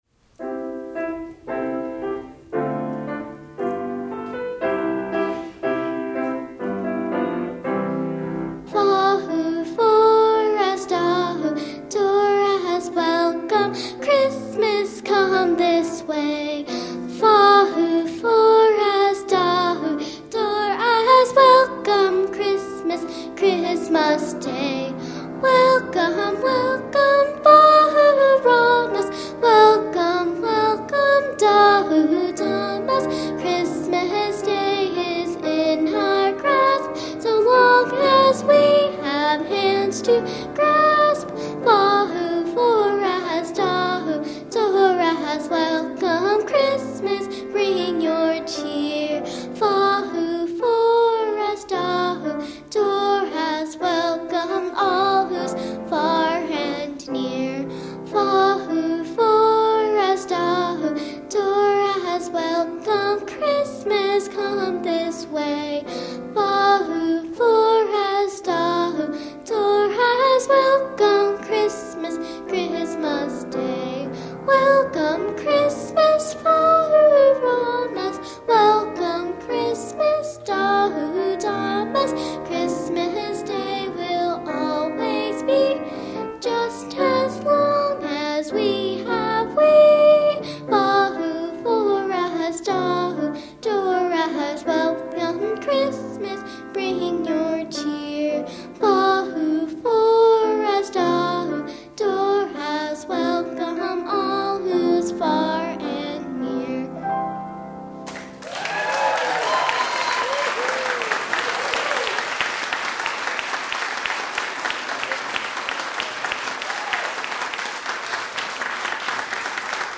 Center for Spiritual Living, Fremont, CA
2009 Winter Concert, Wednesday, December 16, 2009